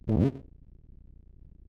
jump_on_bounce_shroom.wav